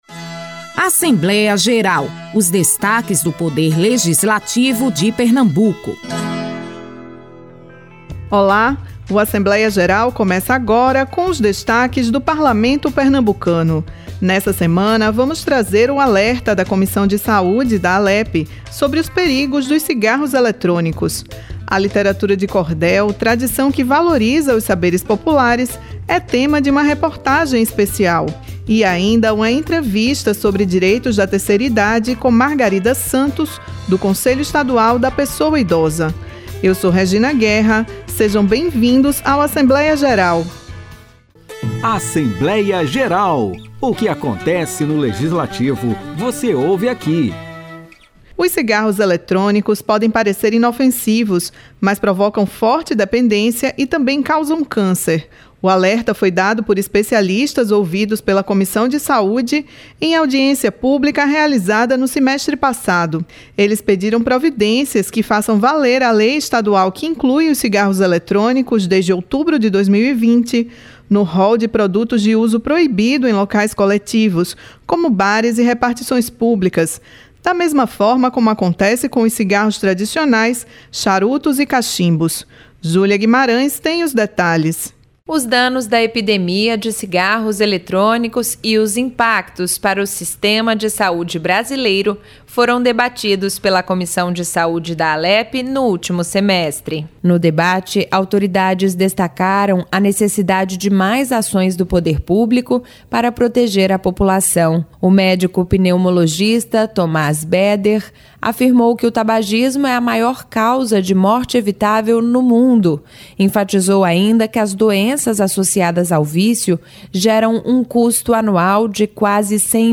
O programa Assembleia Geral desta semana traz uma reportagem especial sobre a literatura de cordel. Considerada patrimônio cultural imaterial brasileiro, essa tradição herdada dos portugueses valoriza os saberes populares e resiste com temas que exaltam a cultura nordestina.